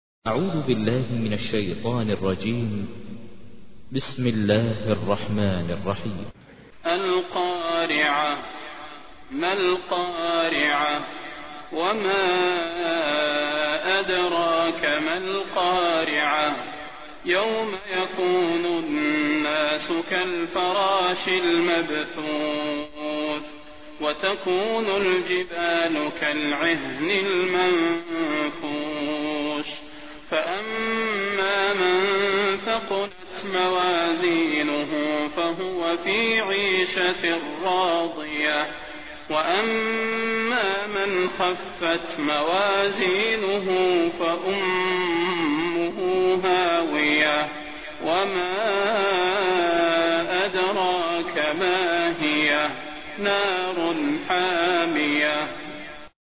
تحميل : 101. سورة القارعة / القارئ ماهر المعيقلي / القرآن الكريم / موقع يا حسين